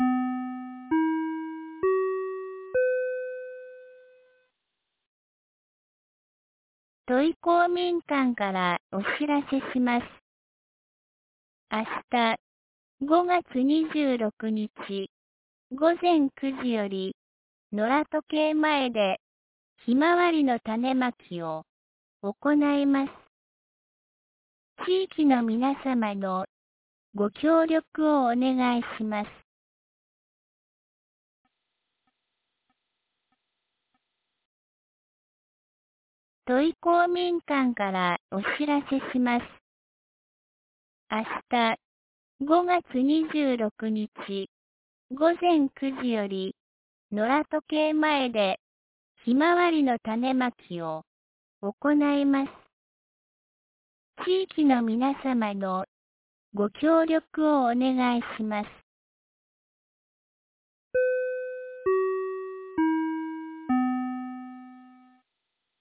2024年05月25日 17時11分に、安芸市より土居、僧津へ放送がありました。